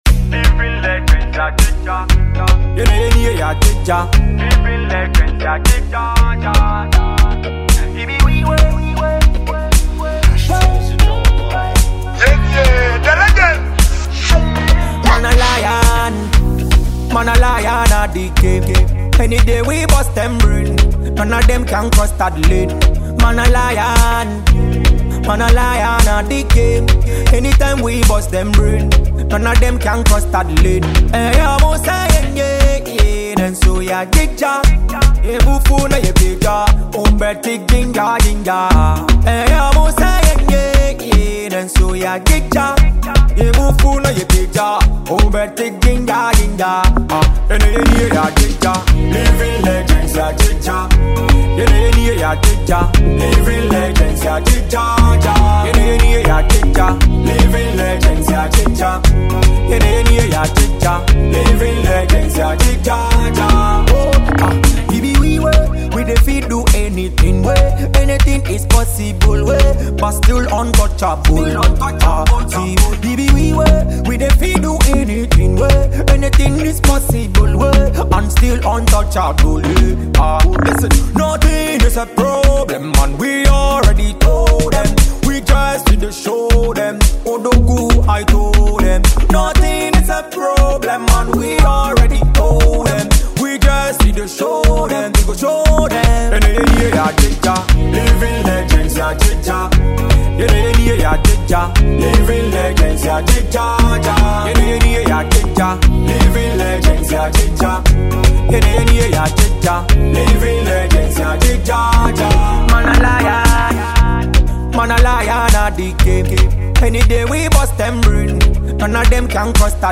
Ghanaian Afrobeat singer and record producer